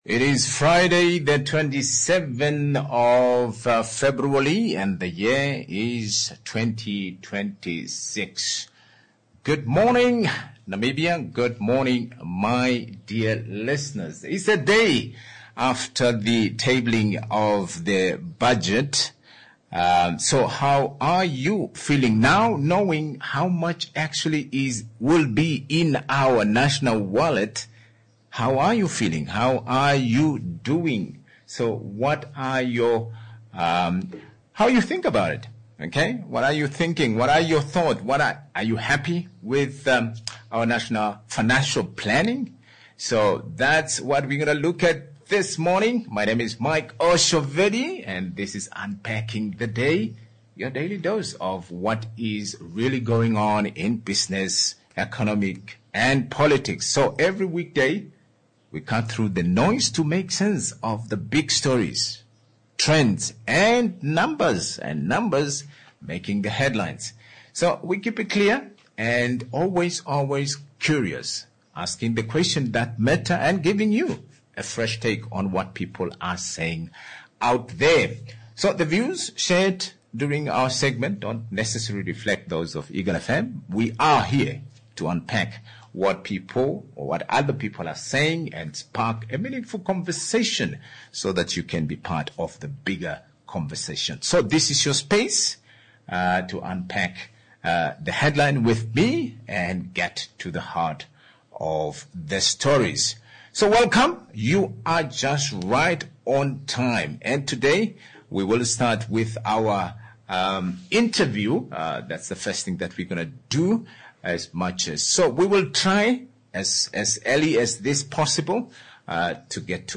National Budget 2026/27: People, Productivity, Prudence, interviewed